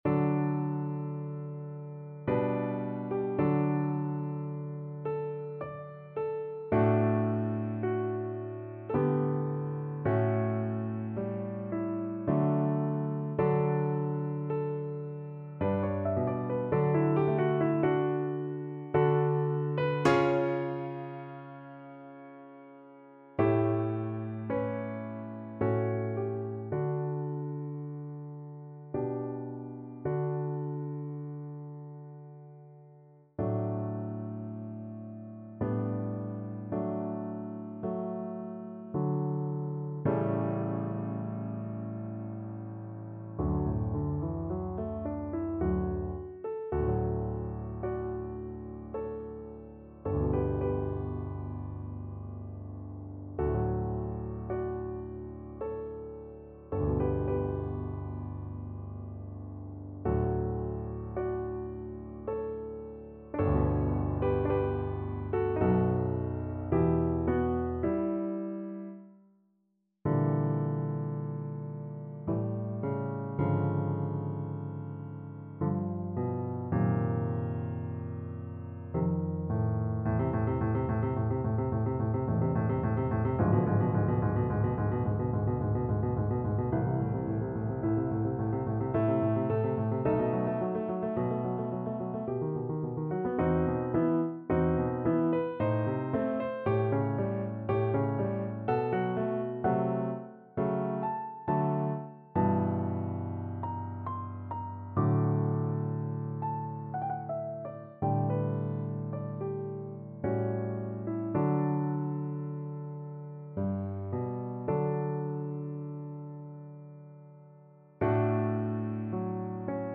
Play (or use space bar on your keyboard) Pause Music Playalong - Piano Accompaniment Playalong Band Accompaniment not yet available reset tempo print settings full screen
D major (Sounding Pitch) (View more D major Music for Flute )
Adagio ma non troppo =108
3/4 (View more 3/4 Music)
Classical (View more Classical Flute Music)